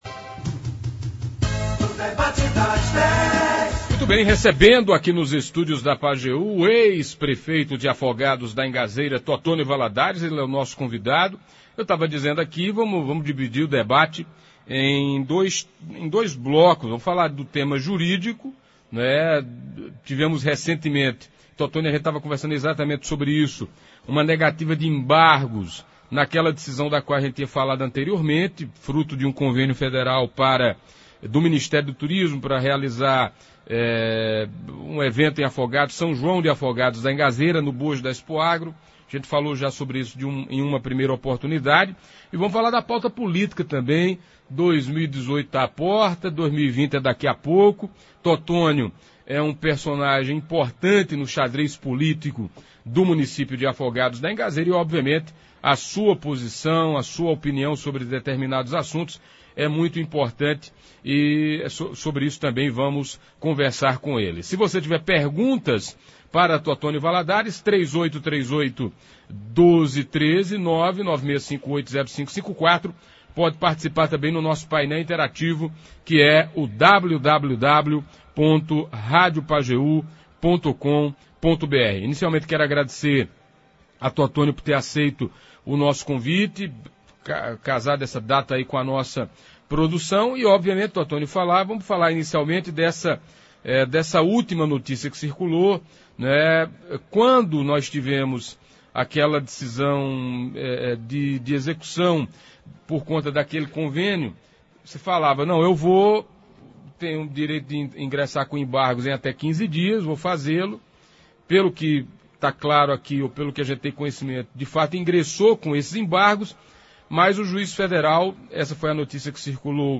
O ex-prefeito de Afogados da Ingazeira Totonho Valadares, falou na manhã desta quinta-feira (27) no Debate das Dez da Rádio Pajeú, sobre a decisão do Juiz Federal da 18ª Seção Judiciária de Pernambuco, Bernardo Monteiro Ferraz, que julgou improcedentes a maioria dos pedidos presentes nos embargos de execução impetrados por Totonho e claro tratou da pauta política para as eleições de 2018 e 2020.